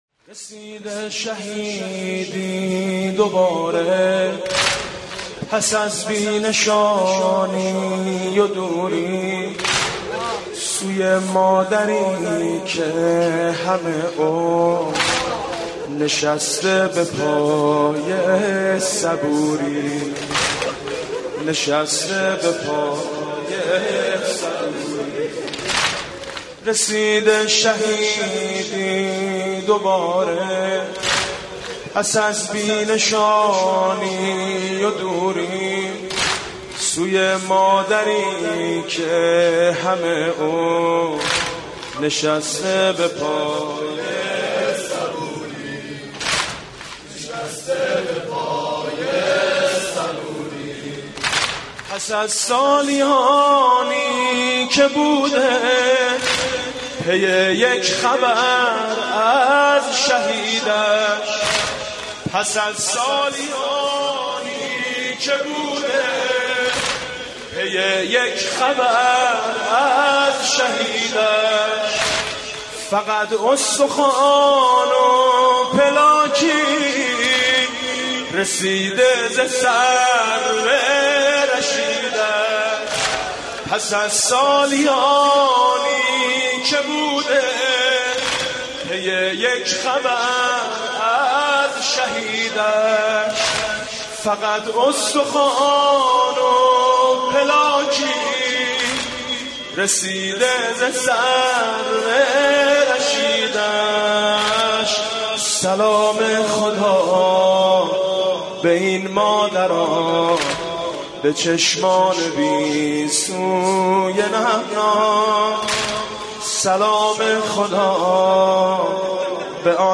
مداحی و نوحه
سینه زنی در شهادت حضرت فاطمه زهرا(س)